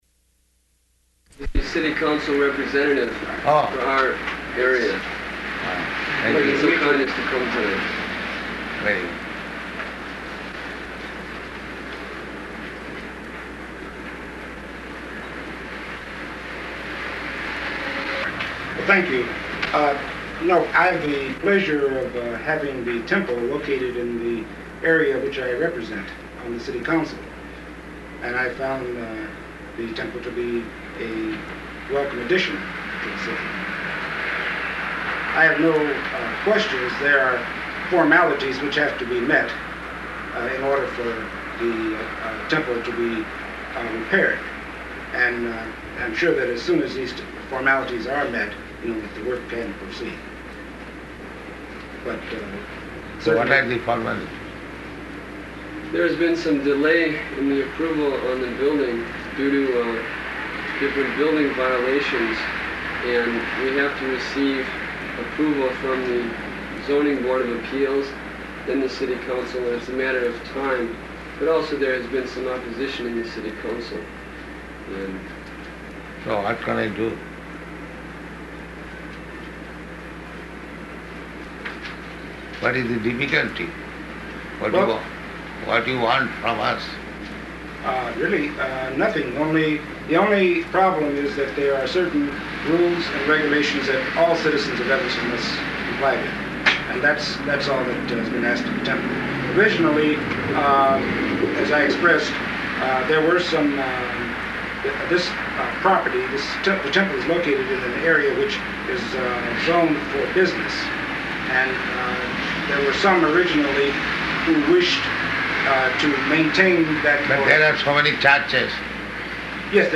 Room Conversation with City Counselor
Location: Chicago